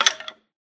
skeletondeath.ogg